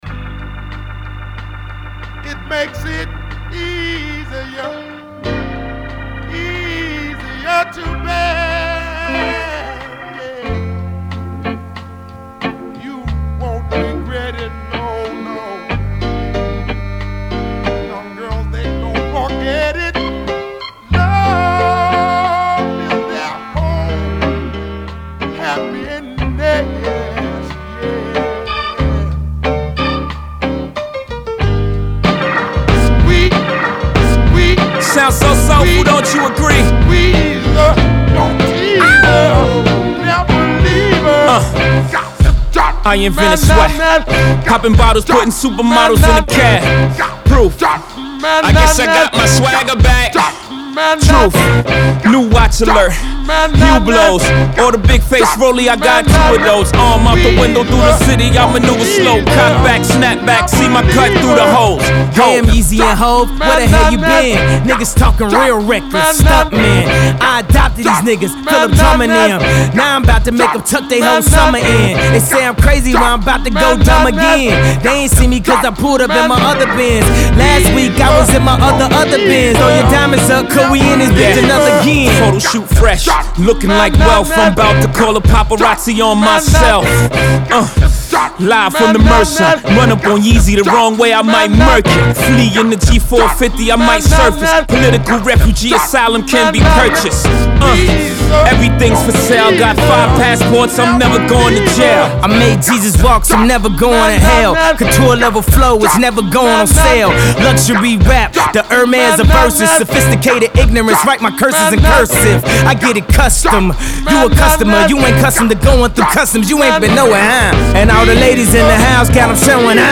soul-based tracks